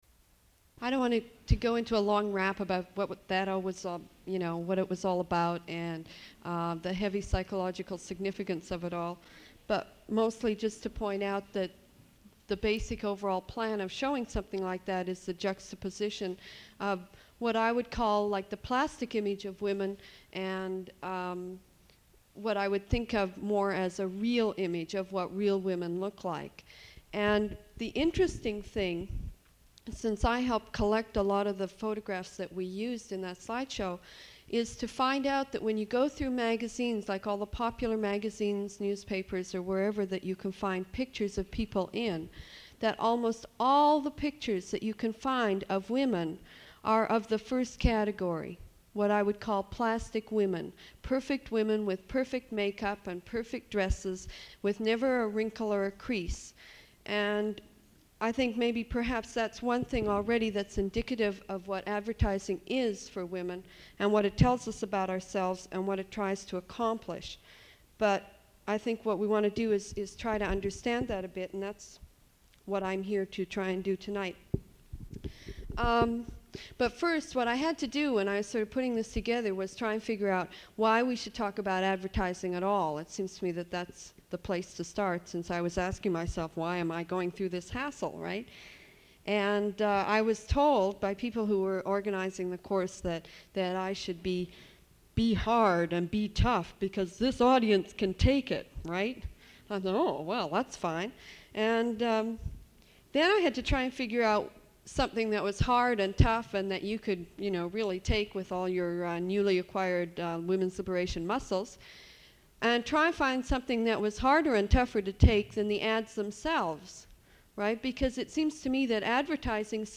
Recording of an address